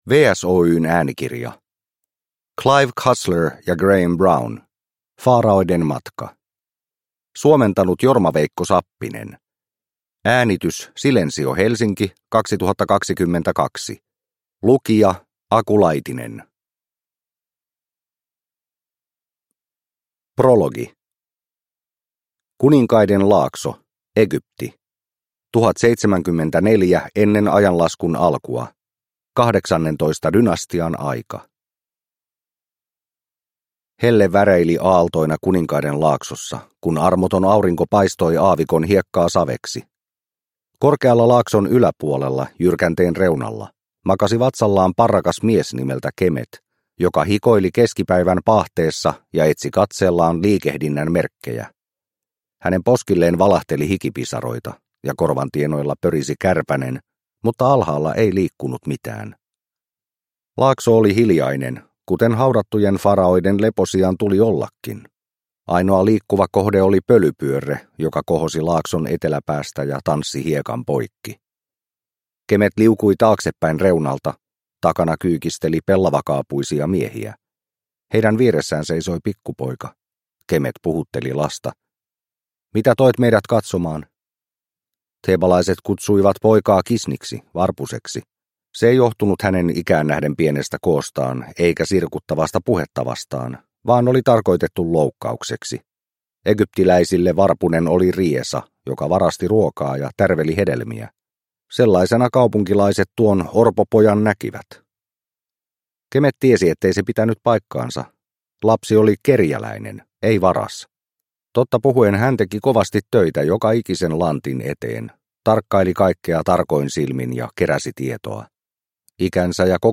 Faraoiden matka – Ljudbok – Laddas ner